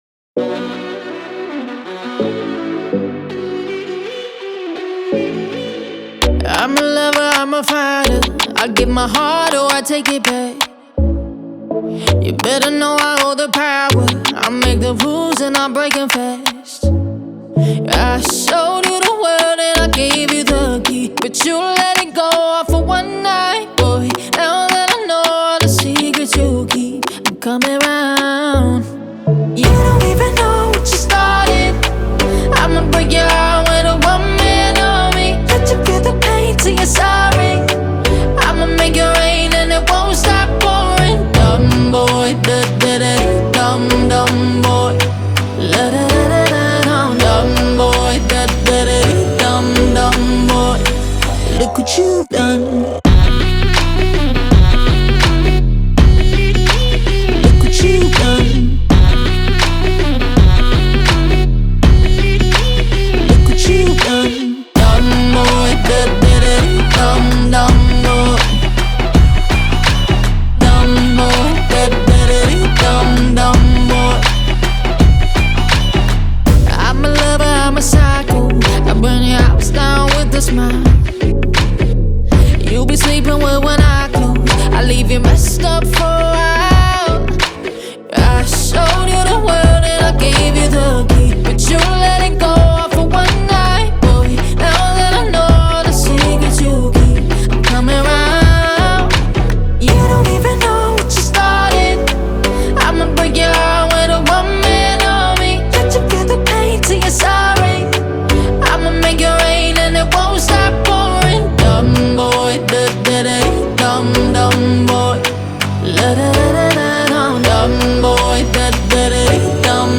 Albania-American up and coming pop singer and songwriter